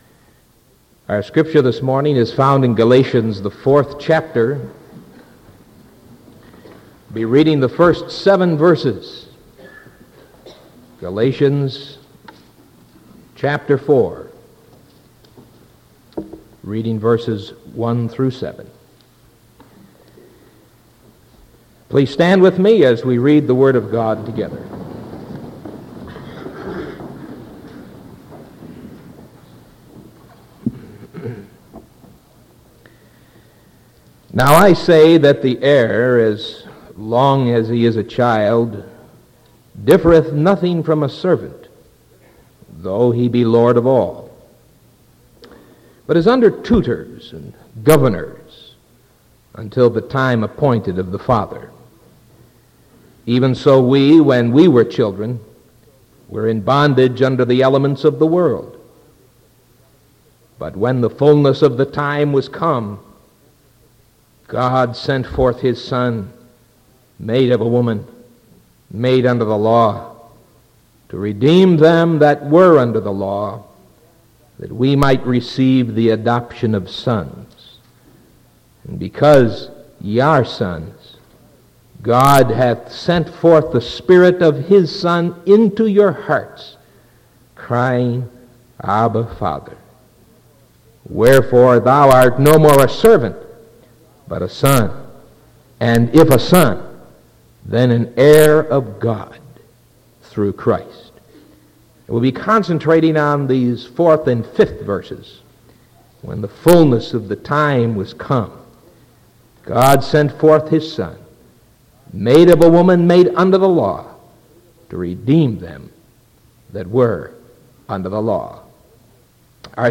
Sermon from November 30th 1975 AM